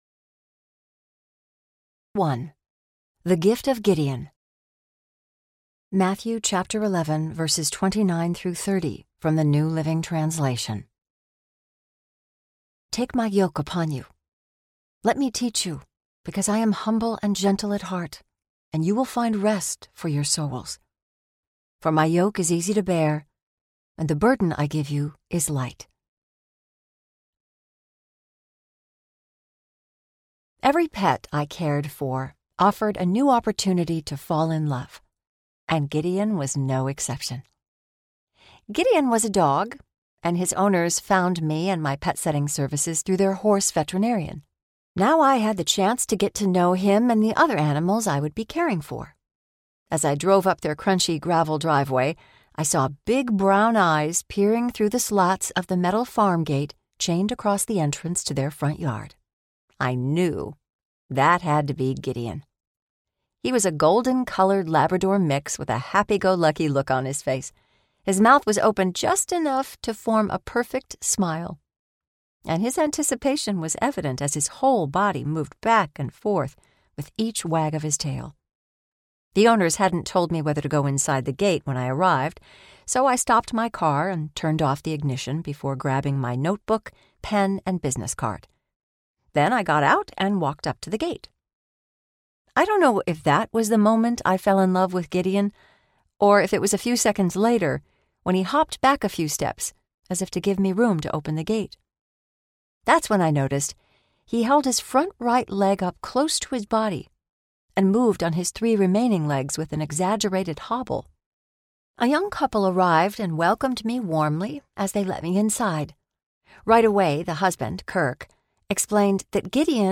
My Hair-Raising and Heart-Warming Adventures as a Pet Sitter Audiobook
Narrator
4.5 Hrs. – Unabridged